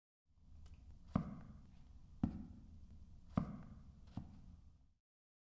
学校的声音 " 地毯的脚步声轰隆隆
描述：学生走路
标签： 地毯 行走 脚步声 行走
声道立体声